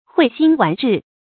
蕙心紈質 注音： ㄏㄨㄟˋ ㄒㄧㄣ ㄨㄢˊ ㄓㄧˋ 讀音讀法： 意思解釋： 心靈如蕙草芬芳，品質似紈素潔白。比喻品行高潔。